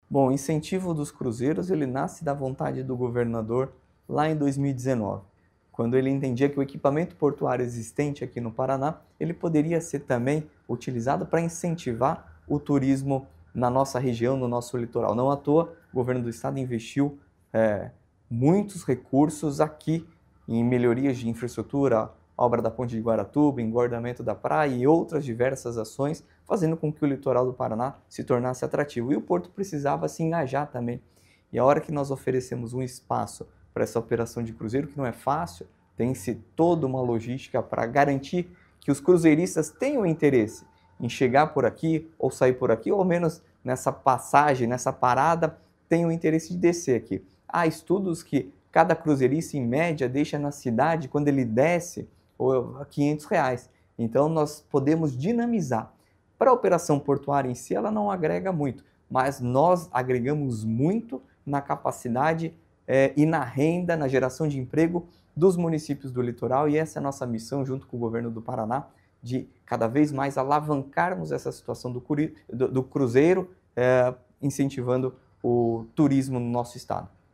Sonora do diretor-presidente da Portos do Paraná, Luiz Fernando Garcia, sobre o Paraná entrar na rota de cruzeiros da MSC para a temporada 2026/2027